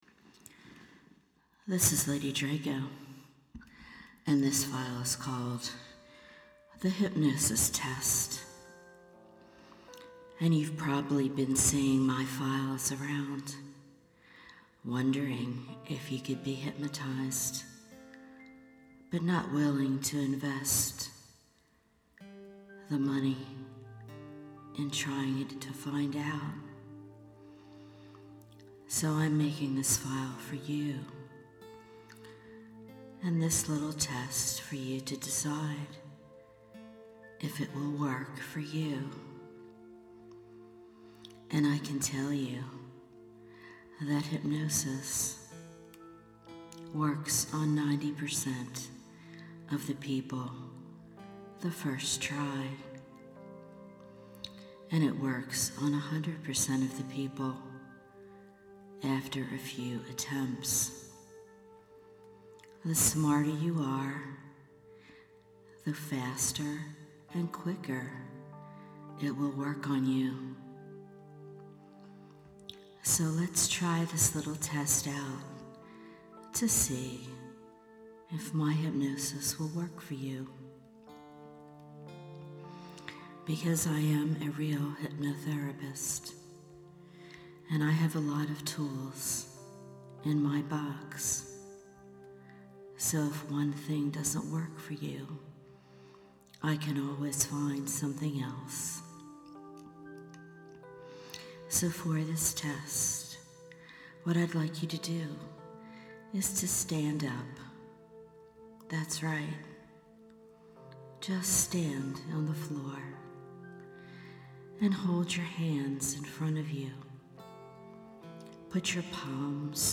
Here is a simple little Test that I designed, for you to Find Out! This 10 minute MP3 File with light background music and Subliminal Messages that will Prove to you, that you are one of the 98% of the Population, that is smart enough, to be able to drop into trance with a skillful Hypnotherapist.